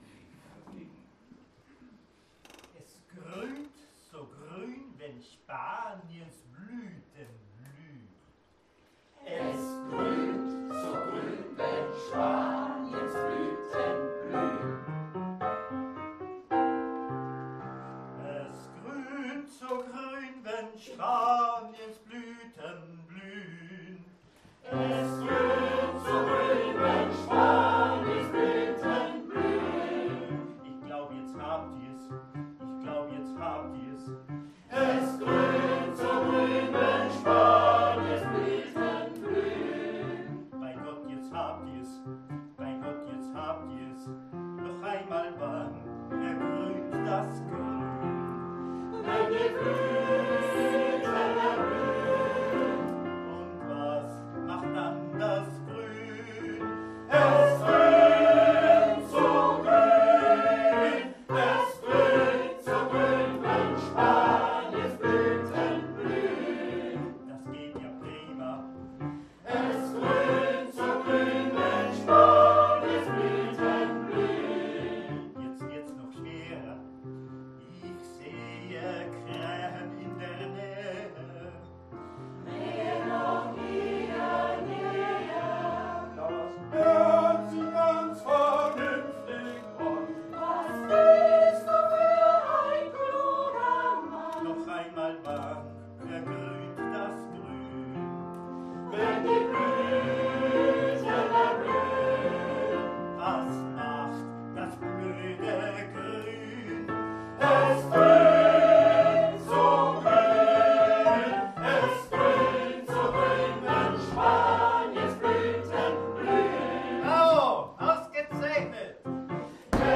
aus der Pfarrkirche Karnburg